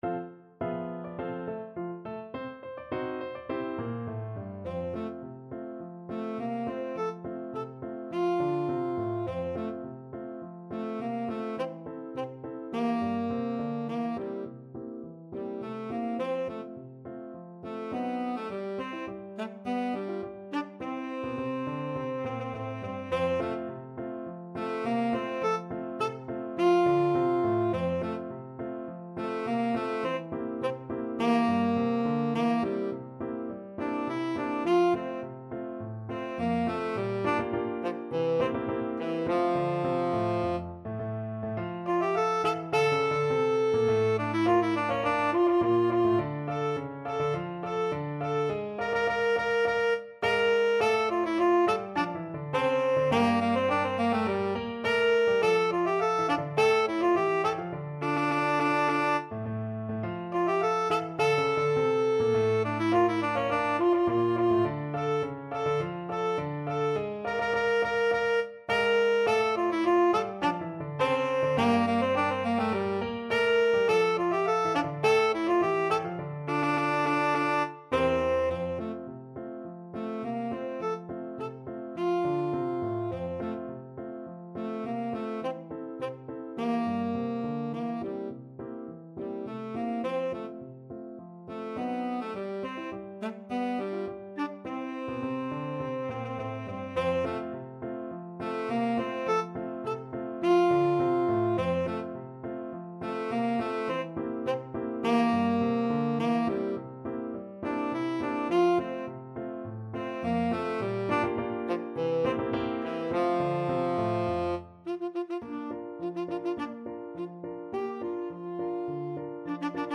Tenor Saxophone version
Quick March = c.104
2/2 (View more 2/2 Music)
Classical (View more Classical Tenor Saxophone Music)